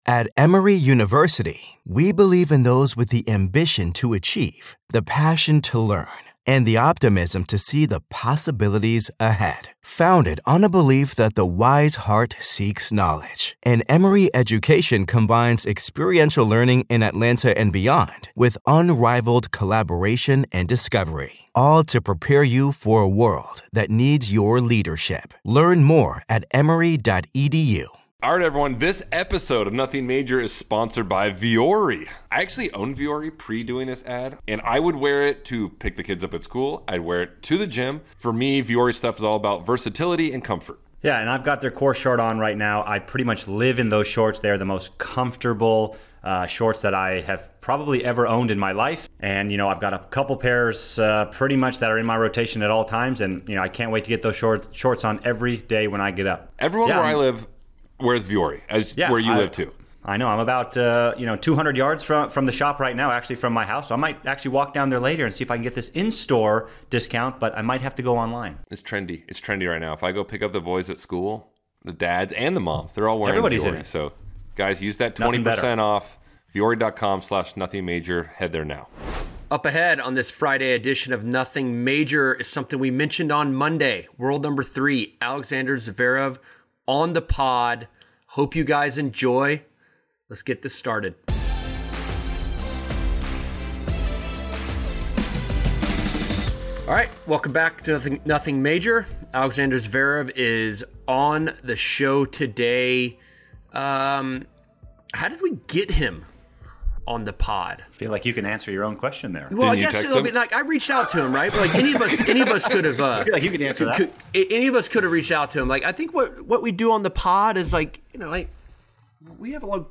World No. 3 Alexander Zverev stops by for what might be the funniest and most dehydrated interview of his life.